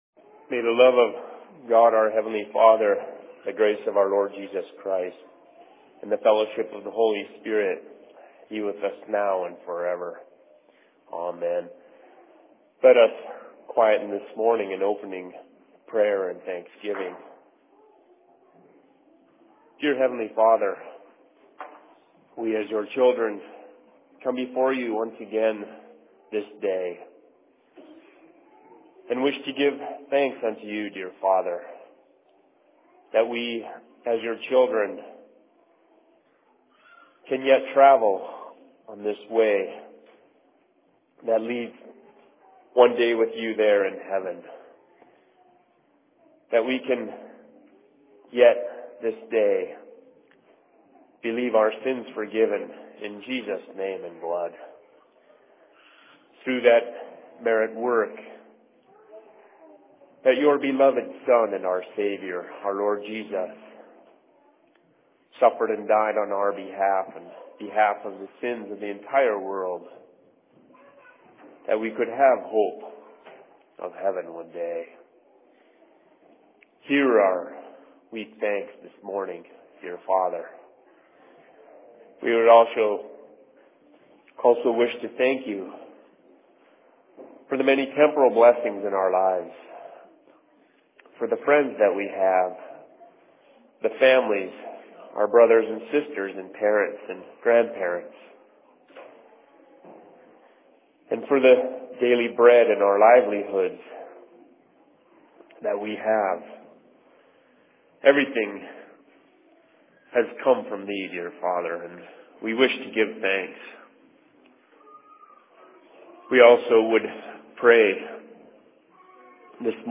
Sermon in Seattle 01.07.2012
Location: LLC Seattle